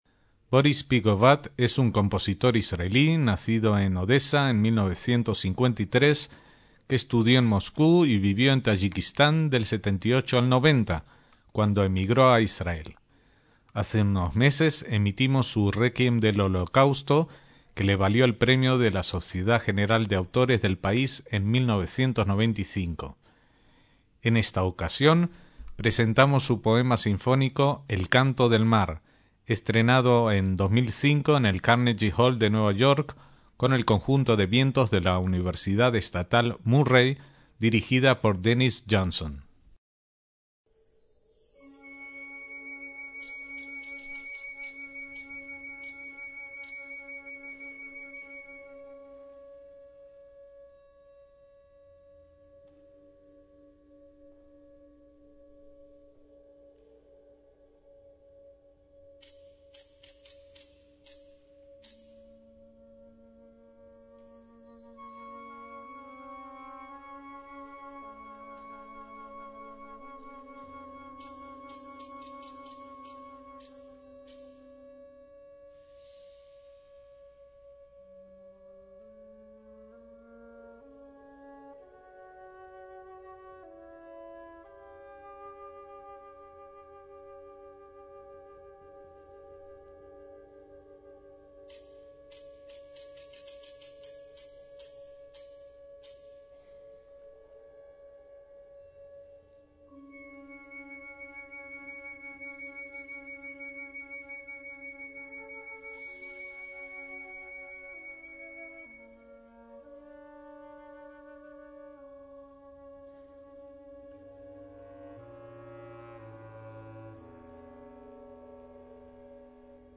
MÚSICA CLÁSICA
poema sinfónico